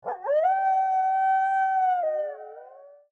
SFX_Wolf_Howl_03.wav